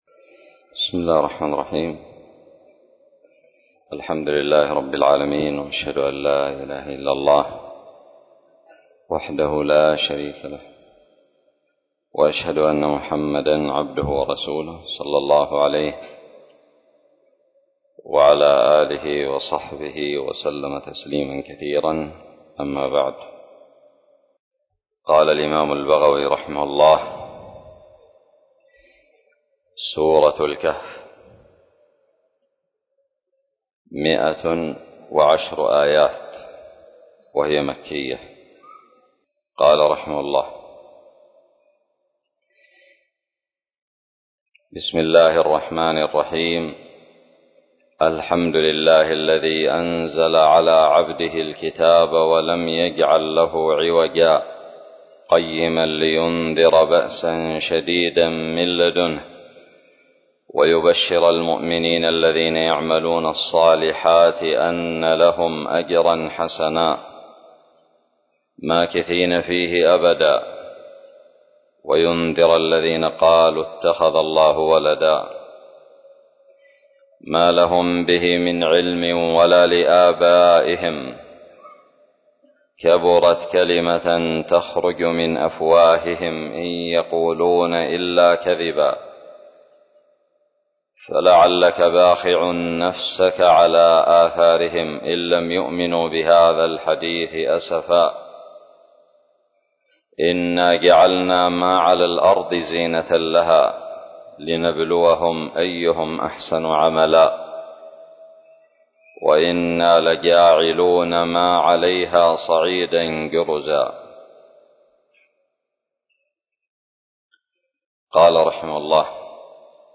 8 الدرس الأول من تفسير سورة الكهف من تفسير البغوي من آية 1 إلى آية
ألقيت بدار الحديث السلفية للعلوم الشرعية بالضالع